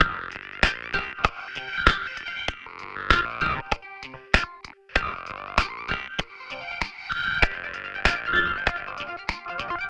FK097LOFI1-R.wav